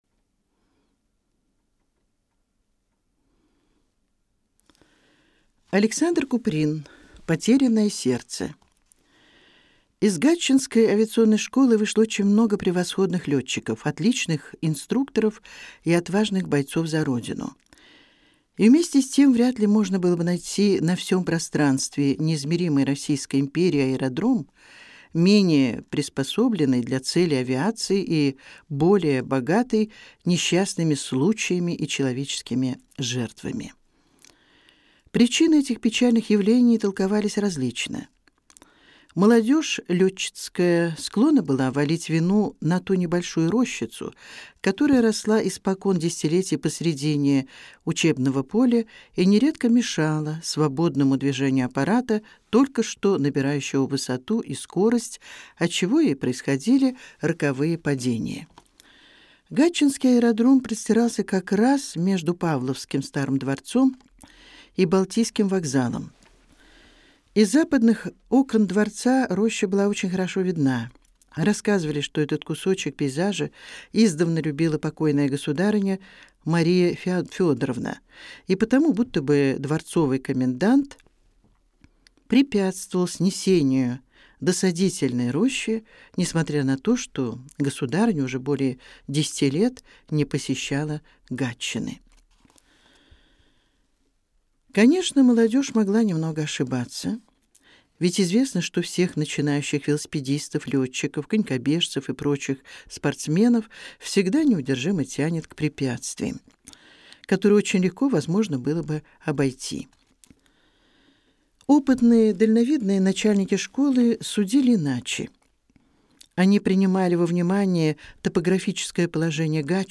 Аудиокнига Потерянное сердце | Библиотека аудиокниг